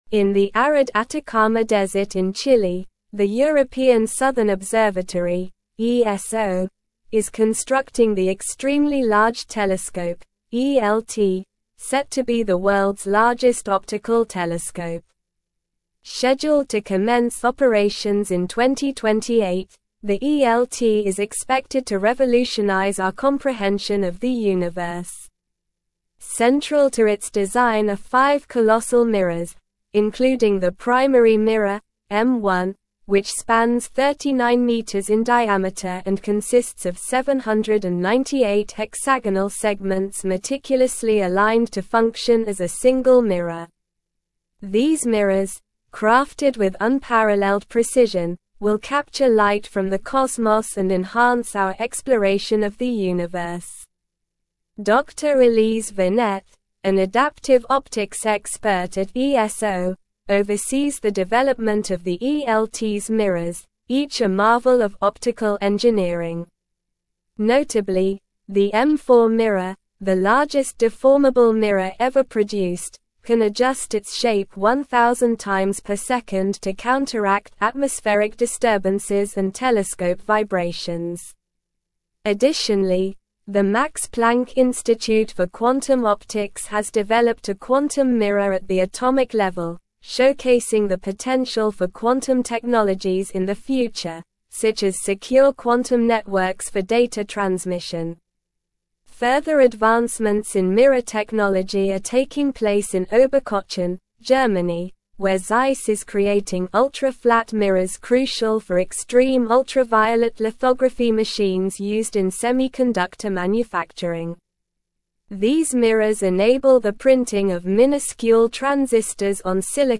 Slow
English-Newsroom-Advanced-SLOW-Reading-Advancing-Technology-Through-Precision-The-Power-of-Mirrors.mp3